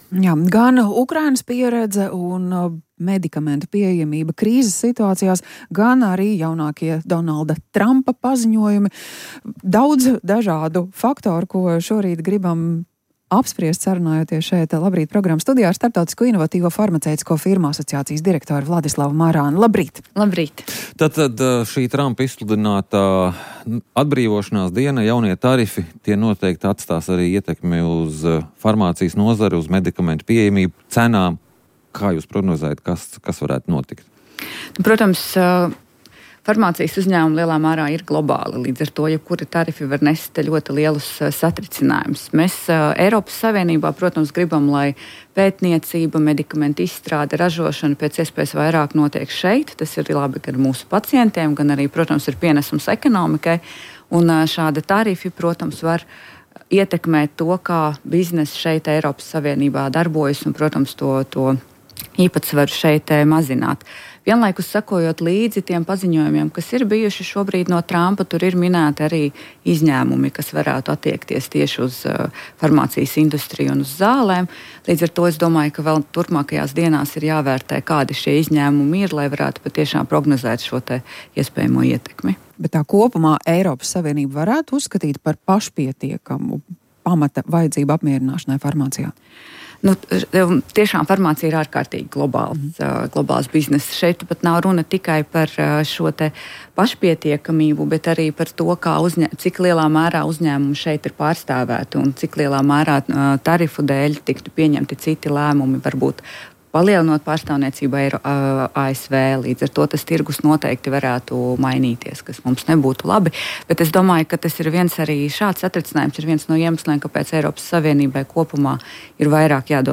Rīta intervija